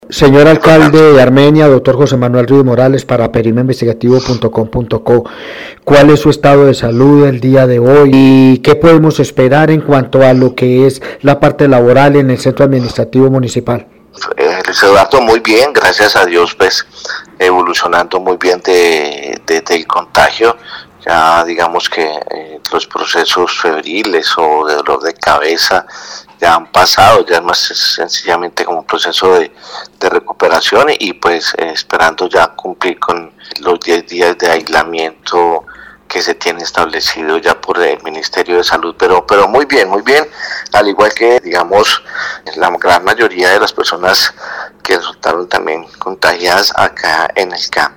Periodismo Investigativo dialogó con el Alcalde de Armenia Dr. José Manuel Ríos Morales y sobre su salud explicó que:
Alcalde de Armenia, Quindío, José Manuel Rios Morales